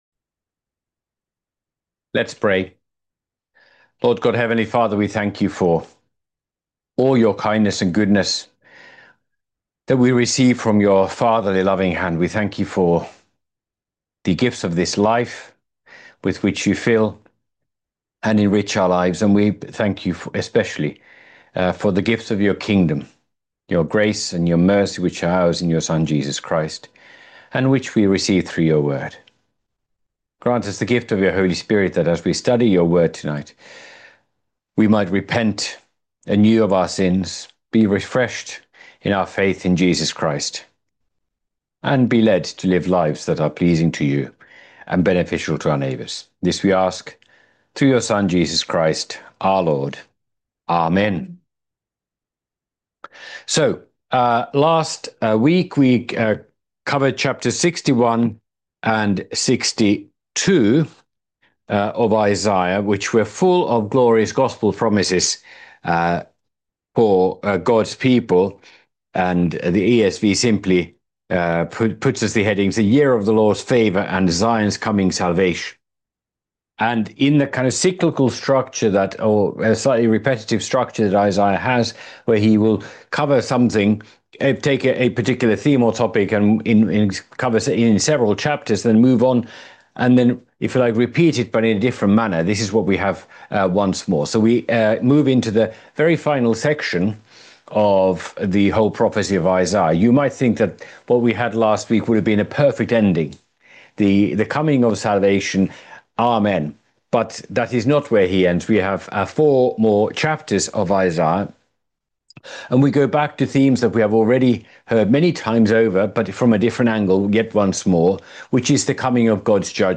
by admin | Apr 23, 2026 | Bible Studies, Isaiah